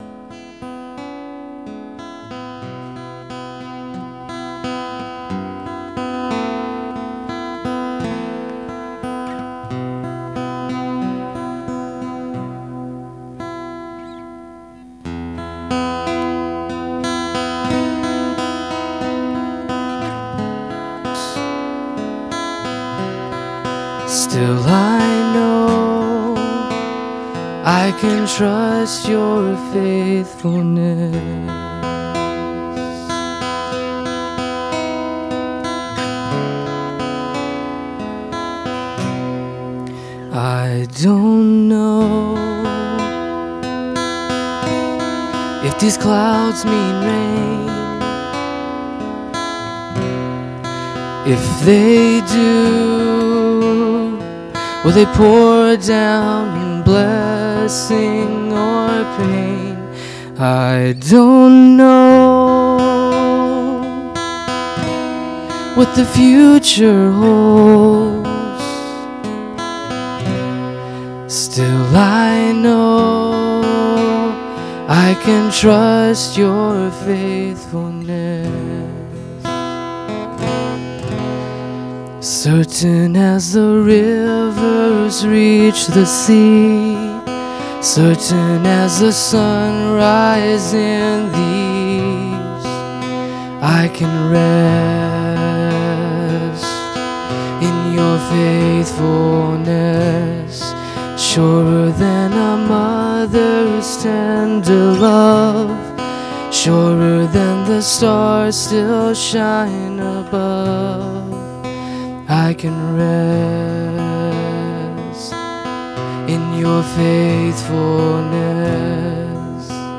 Guitar Playing